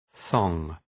Προφορά
{ɵɒŋ}